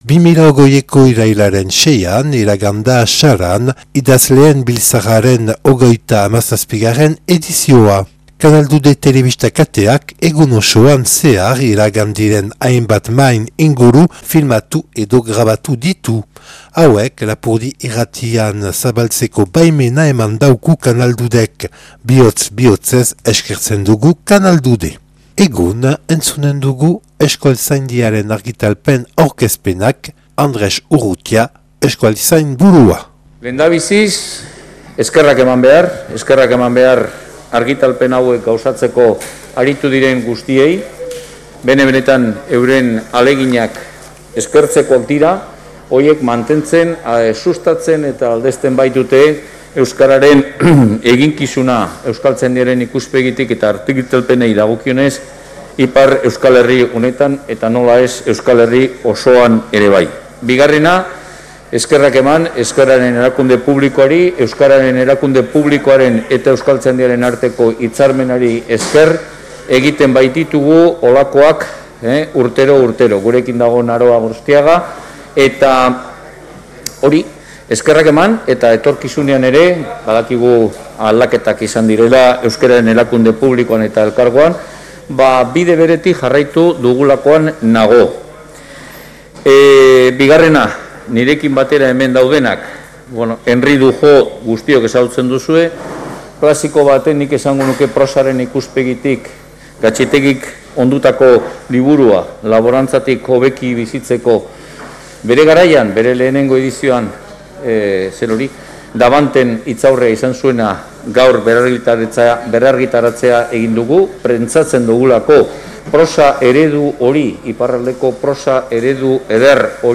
Sarako 37. Idazleen biltzarra irailaren 6an - Euskaltzaindiaren argitalpenen aurkezpenak (1)